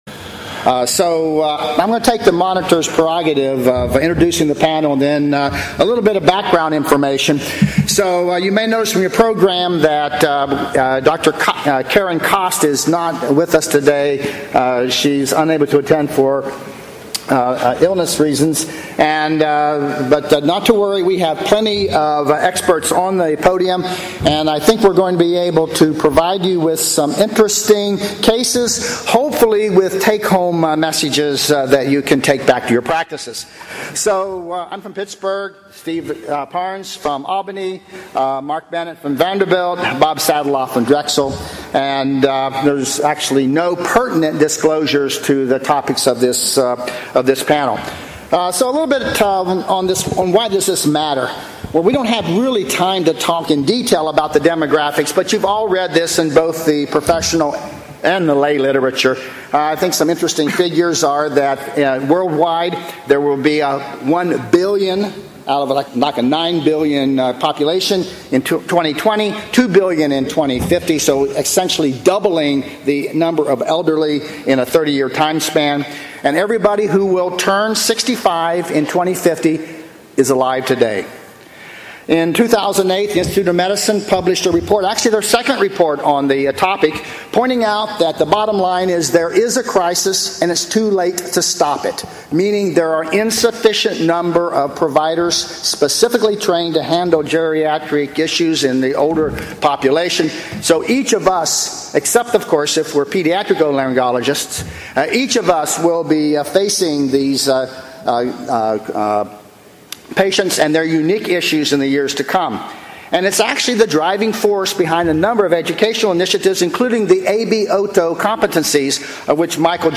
Panelists at the Triological Society's 2013 Combined Sections Meeting present three difficult cases.